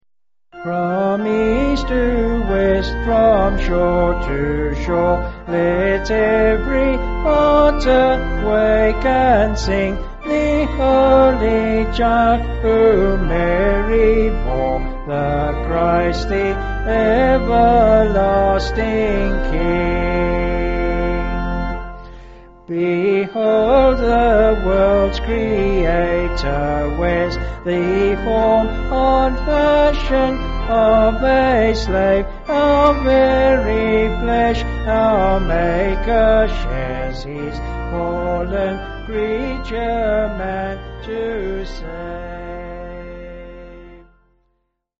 Liturgical Music
Vocals and Organ